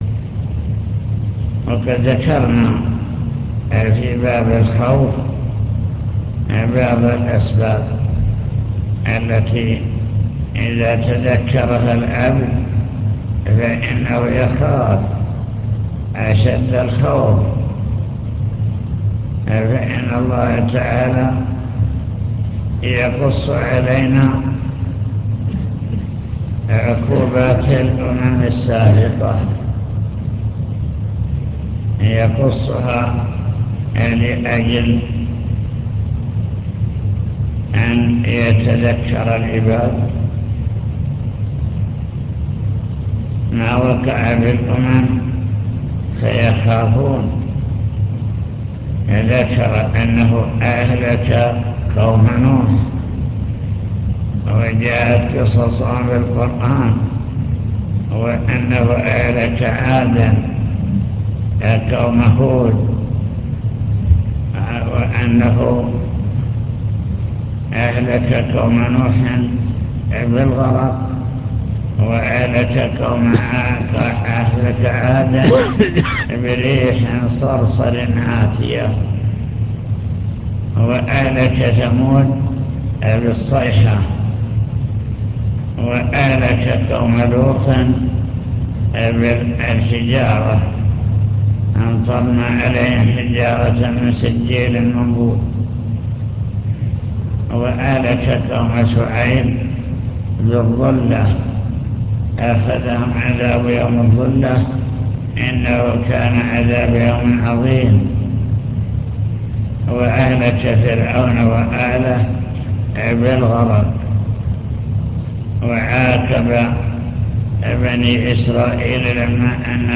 المكتبة الصوتية  تسجيلات - محاضرات ودروس  كتاب التوحيد للإمام محمد بن عبد الوهاب باب قول الله تعالى 'أفأمنوا مكر الله فلا يأمن مكر الله إلا القوم الخاسرون'